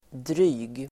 Uttal: [dry:g]